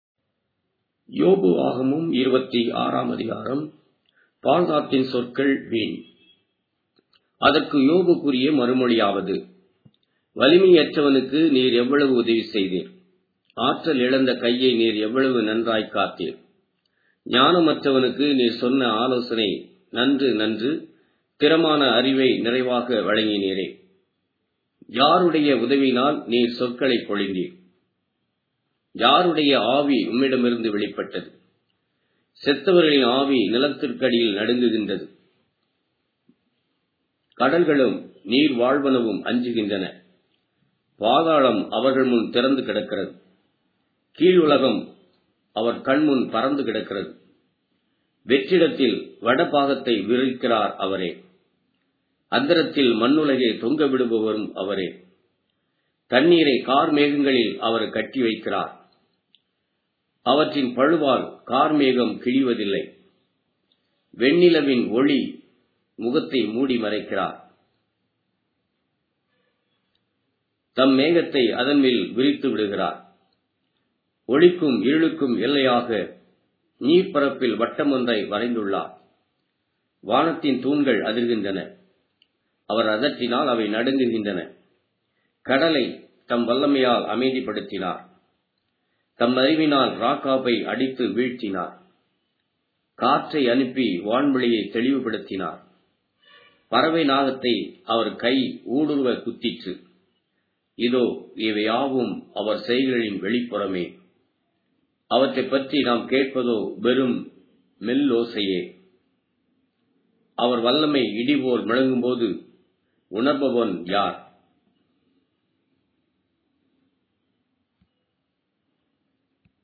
Tamil Audio Bible - Job 8 in Rcta bible version